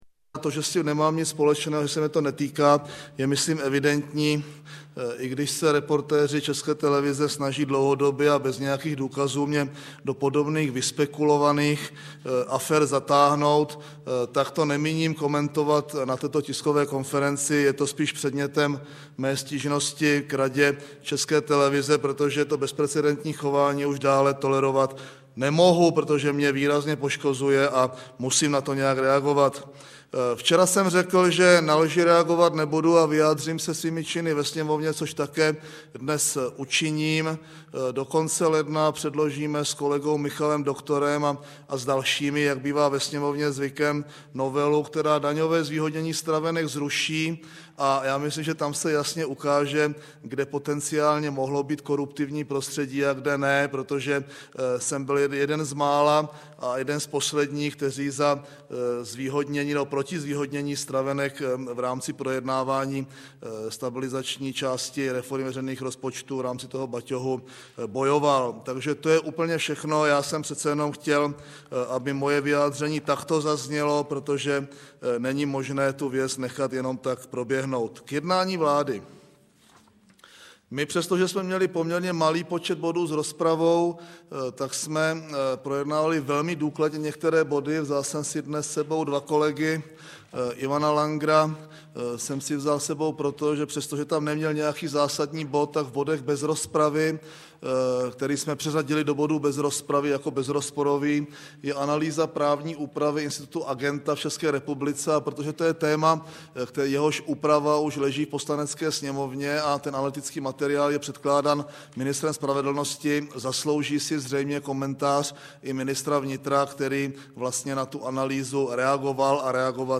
Tisková konference po jednání vlády ČR 16. ledna 2008
Nahrávka, bohužel, nemá standardní kvalitu z technických důvodů.